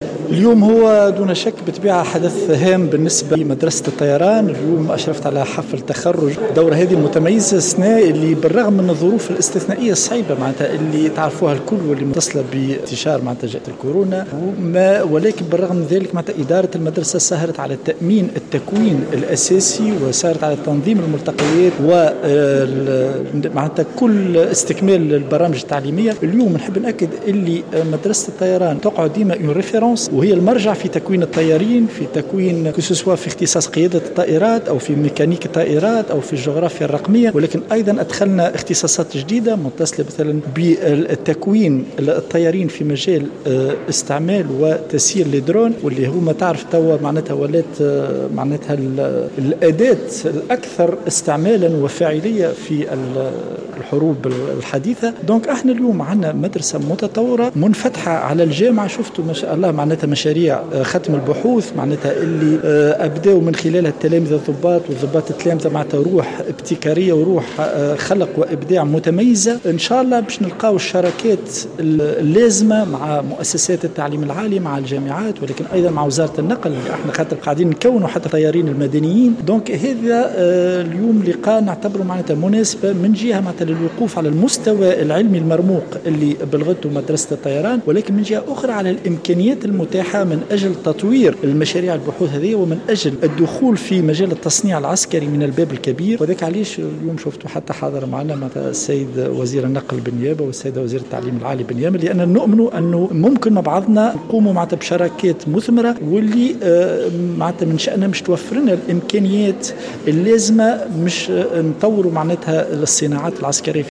قال وزير الدفاع عماد الحزقي في تصريح لمراسلة الجوهرة "اف ام" على هامش اشرافه مساء اليوم على حفل التخرج بمدرسة الطيران ببرج العامري إن إدارة المدرسة سهرت على استكمال البرامج التعليمية وعلى انجاح الدورات التكوينية وتنظيم الملتقيات رغم ظرف الكورونا الإستثنائي.